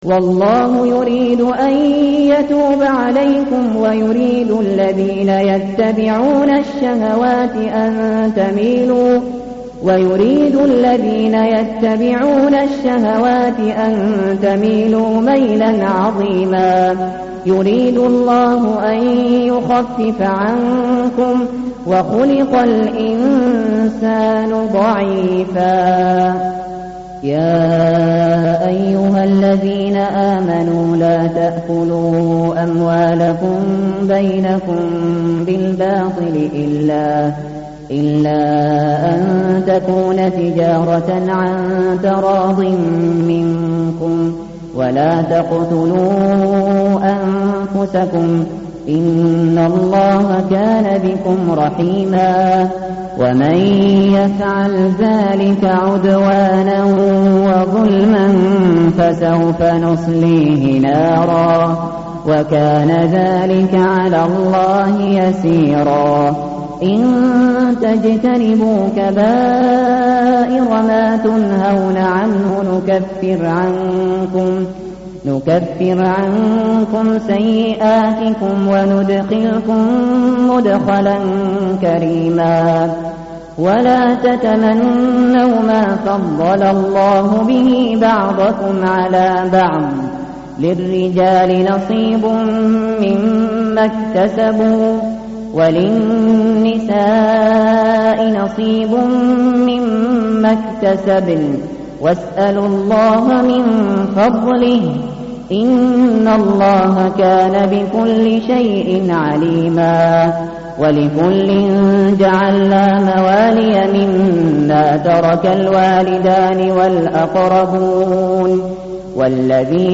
tartil_shateri_page_083.mp3